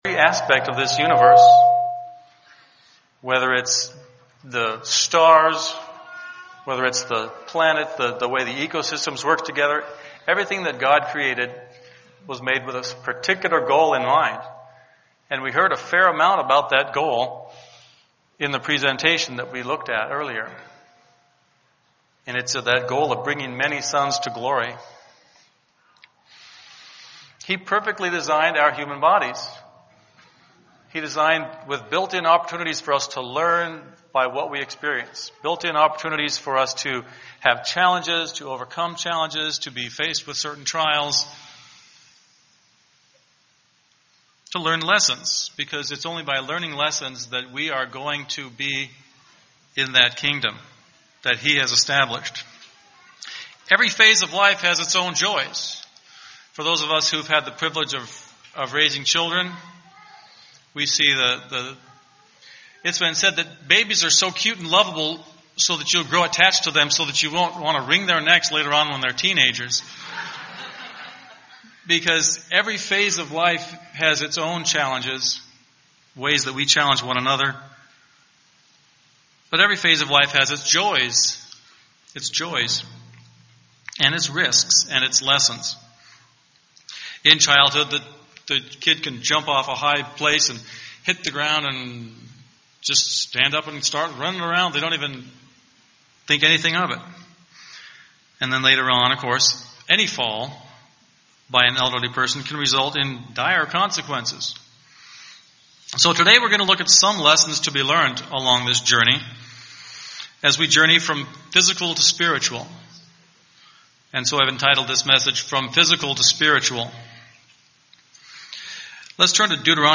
Print Life is a series of learning opportunities as God teaches us to shift our focus from the physical to the spiritual UCG Sermon Studying the bible?